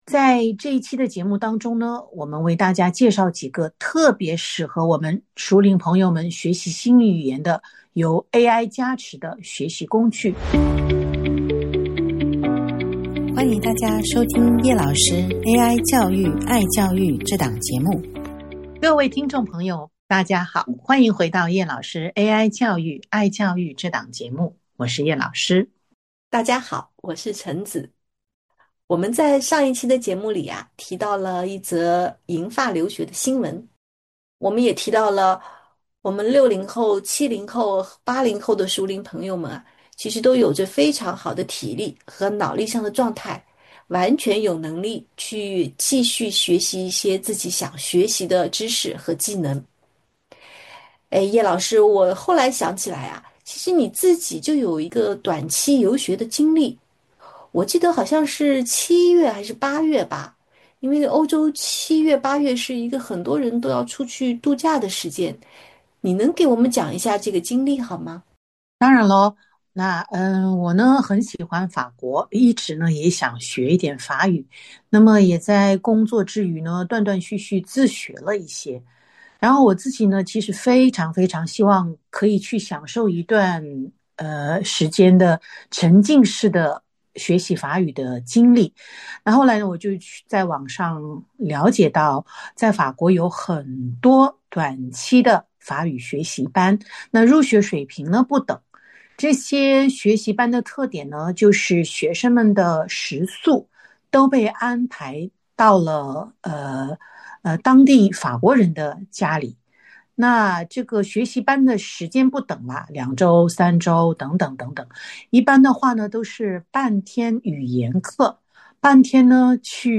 这期节目紧接着上期节目的内容，为熟龄朋友们具体介绍了五个有AI加持的语言学习工具：Duolingo; Babbel; HelloTalk；Tandem；和Speechling。两位主持人详细分析了他们的特点，各自的用途。